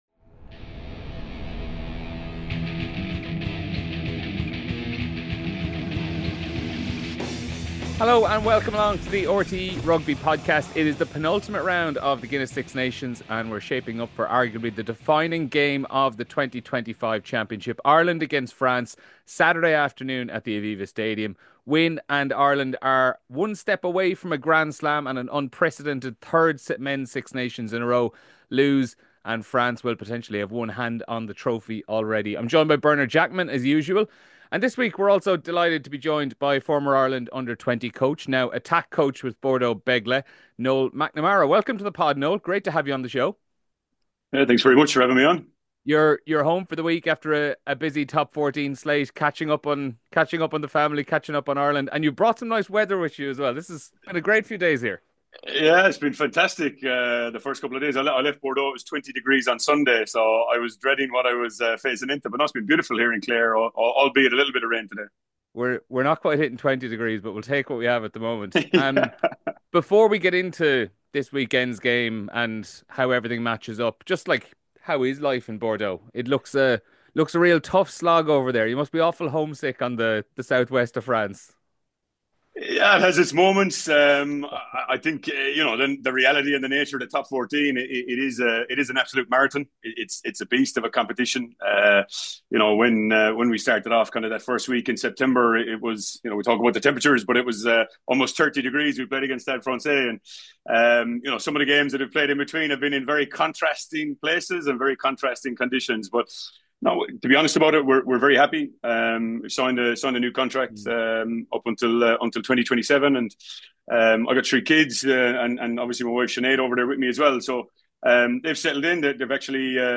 Aki's impact in Cardiff, Keenan and Deegan interviews.